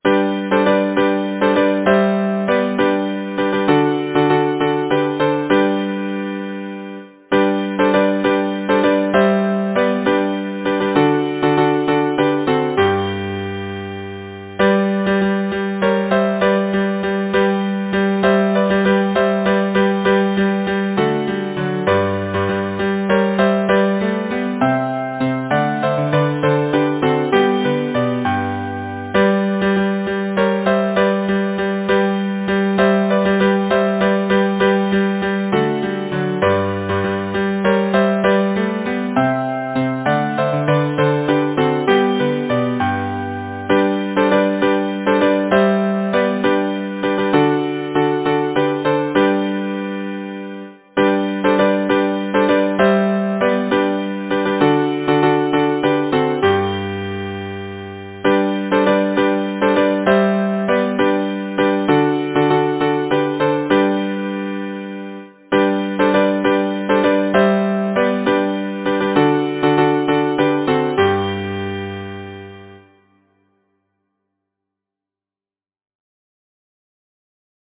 Title: Boat song Composer: Cyrus Cornelius Pratt Lyricist: Number of voices: 4vv Voicing: SATB Genre: Secular, Partsong
Language: English Instruments: A cappella